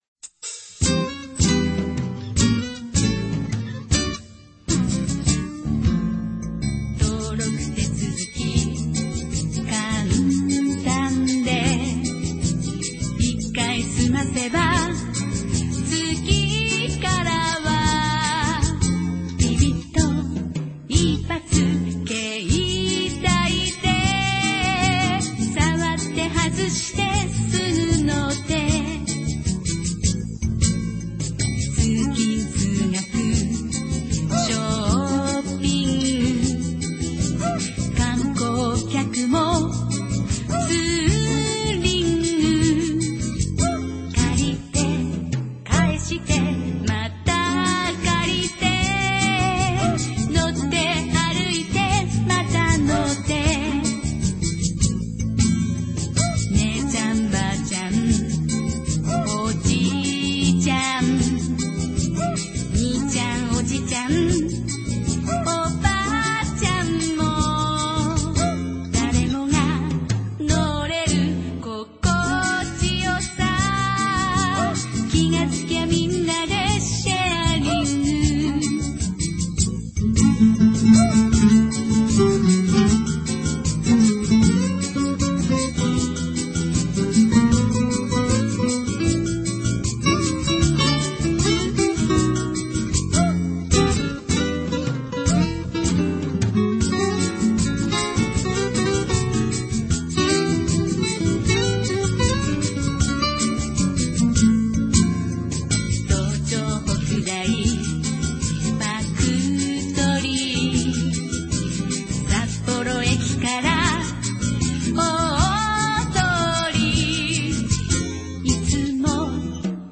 作曲：ハシモト・コウさん（札幌出身のミュージシャン）
porocle_song_bossa_ver.mp3